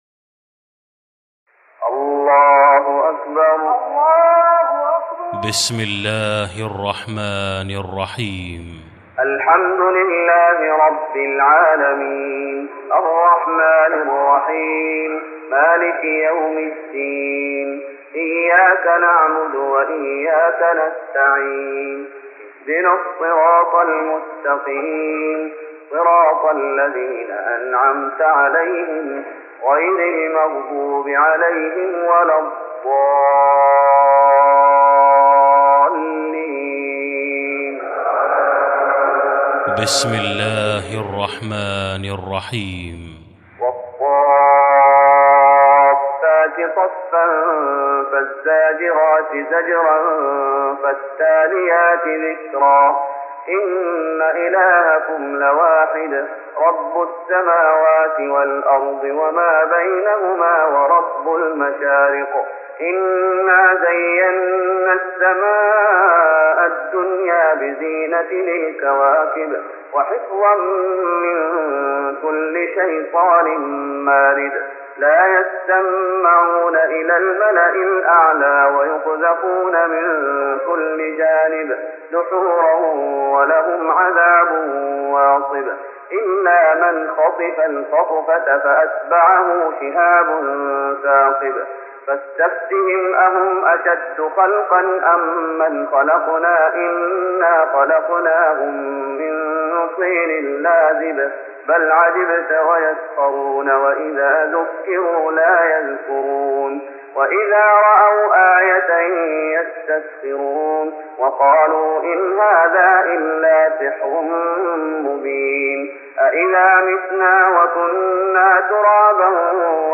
تراويح رمضان 1414هـ من سورة الصافات(1-138) Taraweeh Ramadan 1414H from Surah As-Saaffaat > تراويح الشيخ محمد أيوب بالنبوي 1414 🕌 > التراويح - تلاوات الحرمين